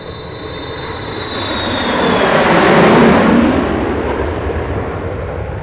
jets.mp3